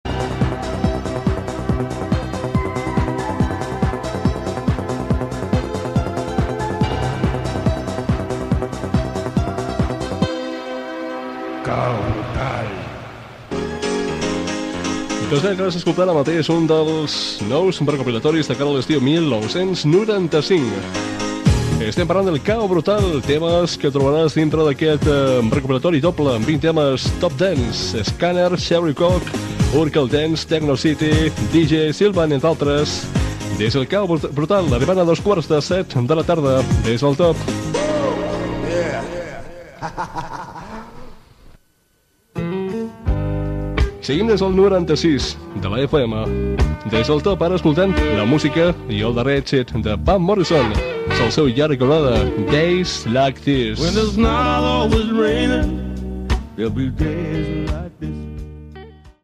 Presentació d'un tema musical, hora, identificació del programa i tema musical
Musical